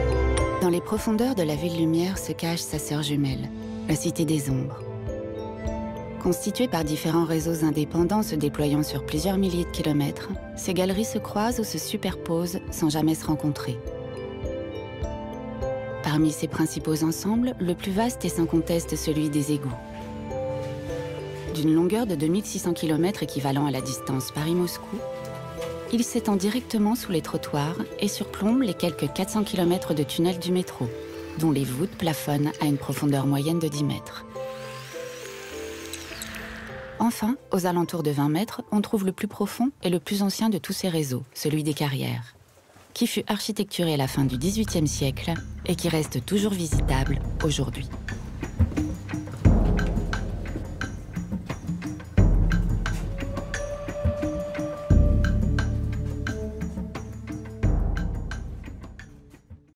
DOCUMENTAIRE (La cité des ombres) – adulte - medium - sérieux